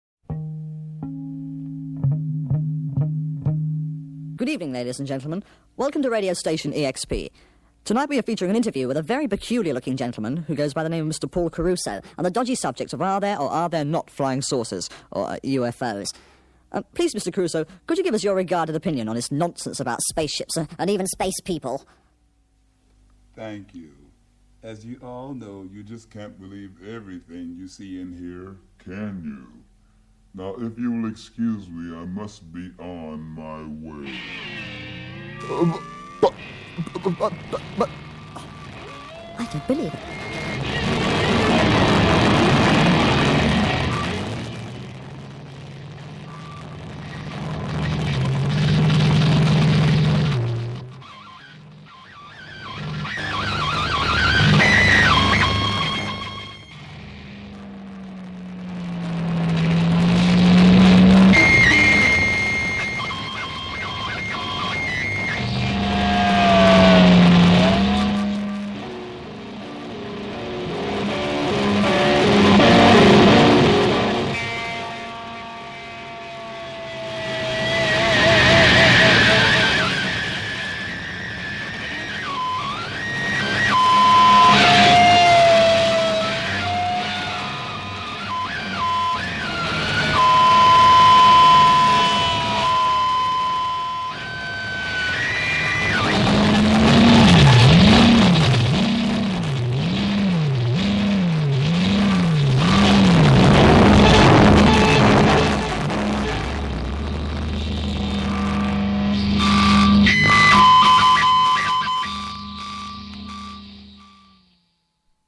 Psychedelic Rock, Blues Rock